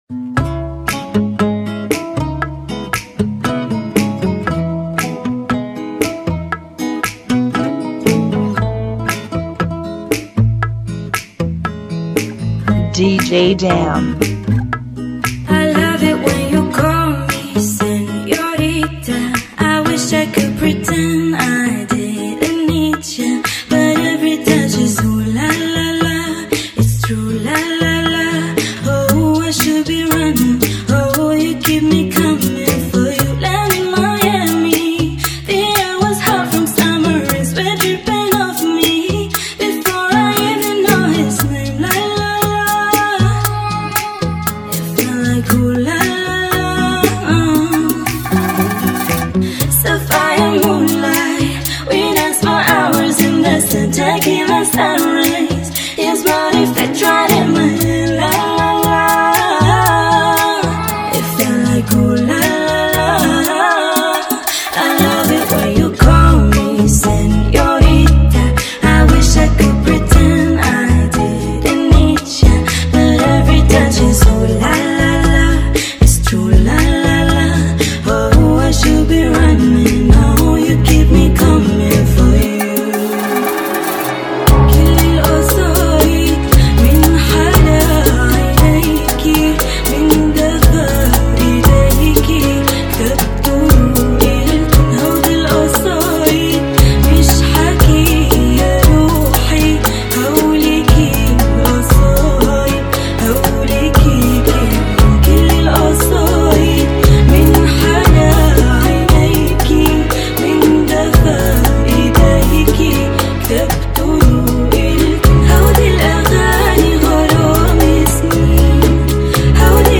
117 BPM
Genre: Bachata Remix